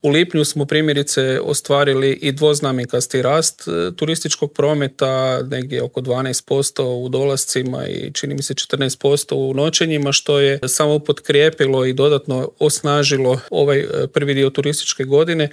Brojke za prvih pola godine su izrazito dobre, a u Intervjuu tjedna Media servisa prokomentirao ih je direktor Hrvatske turističke zajednice Kristjan Staničić: